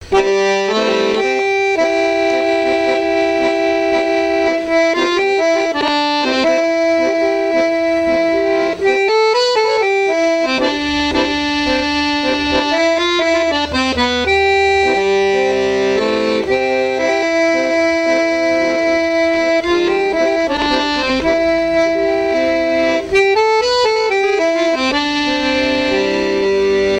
Aizenay ( Plus d'informations sur Wikipedia ) Vendée
danse : tango
Pièce musicale inédite